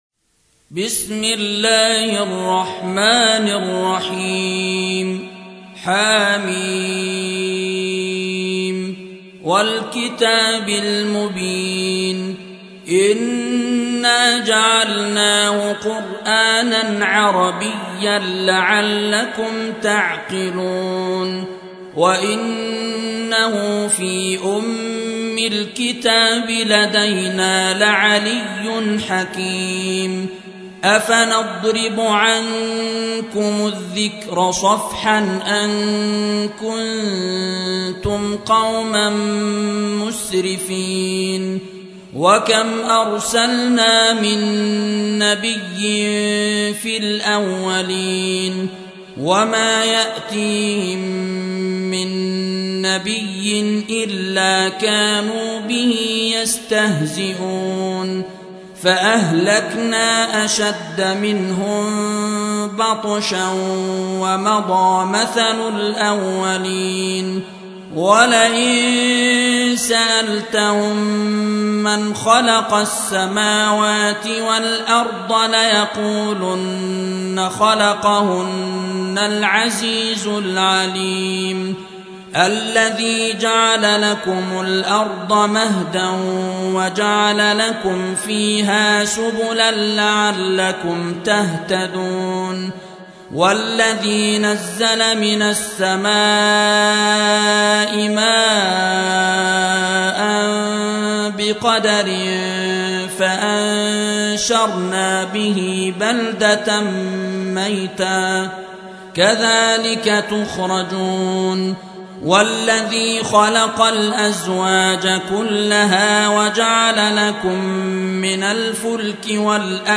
43. سورة الزخرف / القارئ